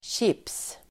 Uttal: [tjip:s]